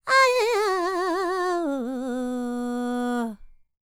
QAWALLI 13.wav